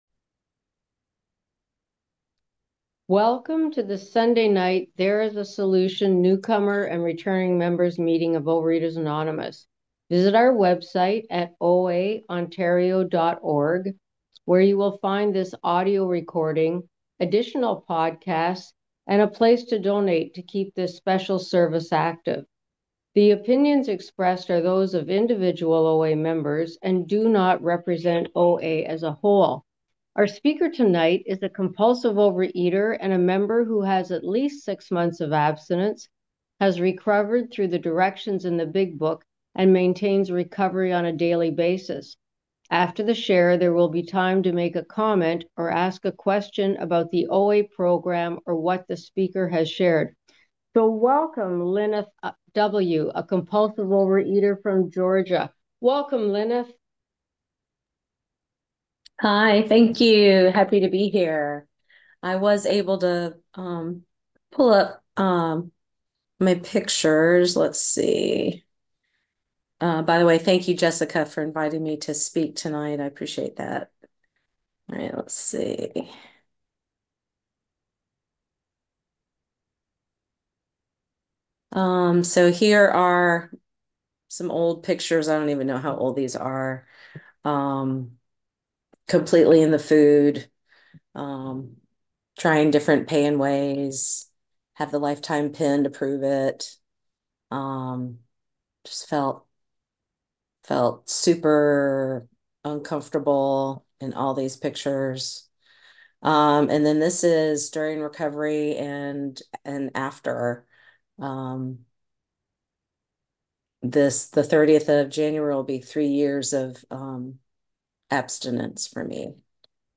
Overeaters Anonymous Central Ontario Intergroup Speaker Files OA Newcomer Meeting